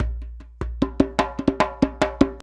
100DJEMB11.wav